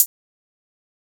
Closed Hats
HiHat (22).wav